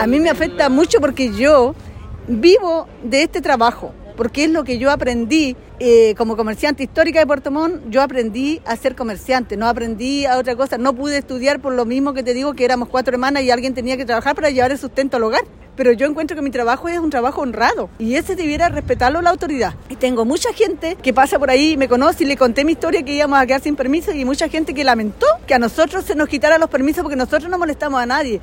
comerciante-historica-cuna.mp3